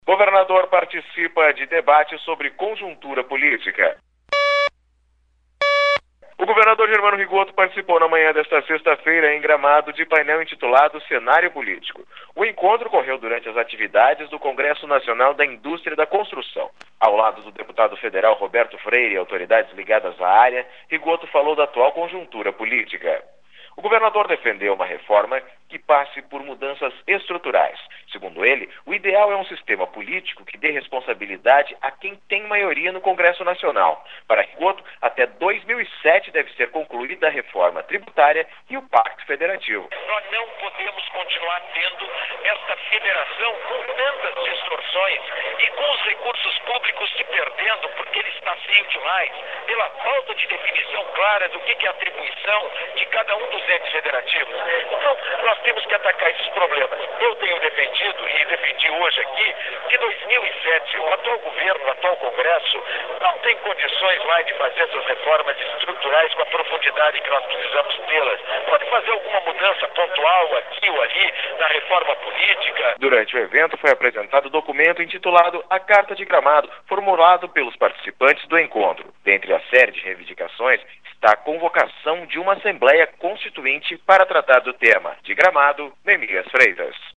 2005-08-19-governador-participa-de-debate-conjuntura-politica.mp3